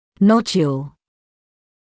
[‘nɔdjuːl][‘нодйуːл]узелок; узелковое утолщение
nodule.mp3